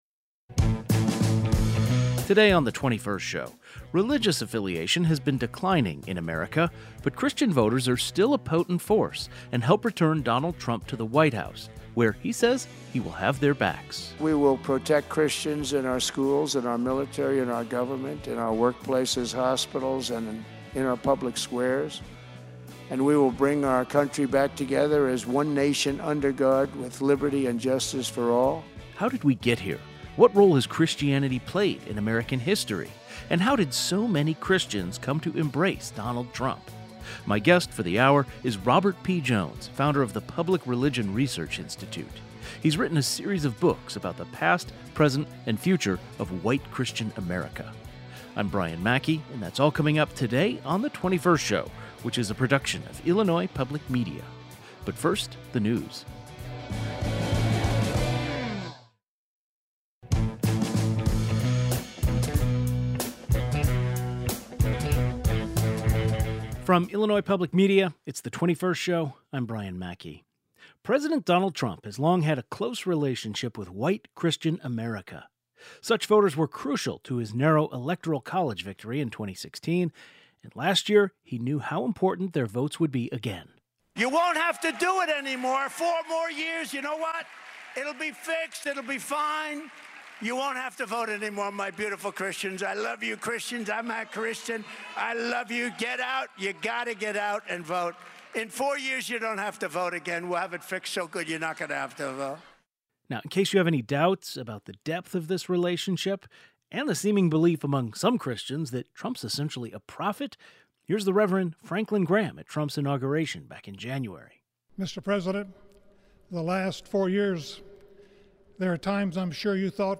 Today's show included a rebroadcast of the following "best of" segment, first aired February 25, 2025: Author explains how so many white evangelical Christians came to embrace Donald Trump.